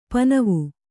♪ panavu